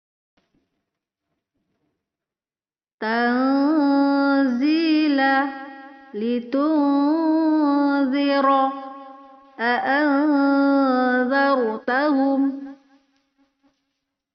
Mim dan nun tanpa sukun, lidah menuju huruf di depannya. Sebagian angin masuk ke hidung dan sebagian lainnya masuk ke mulut.